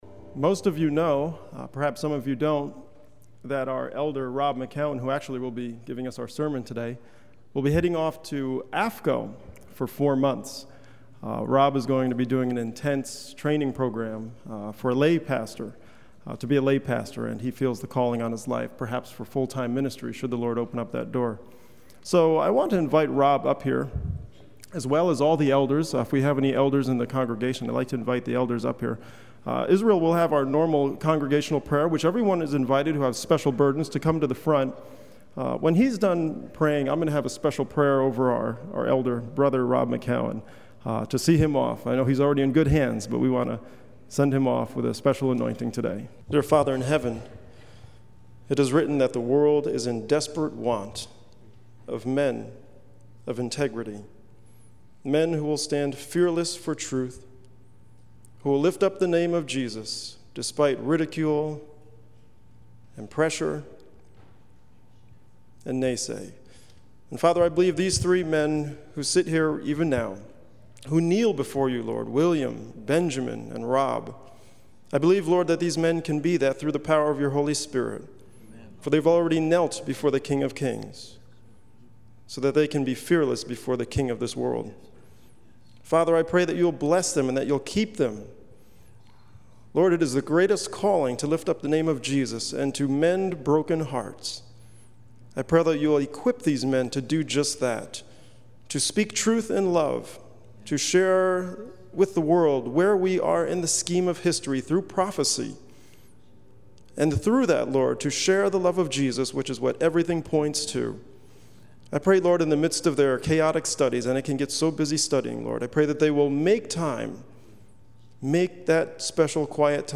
on 2015-08-07 - Sabbath Sermons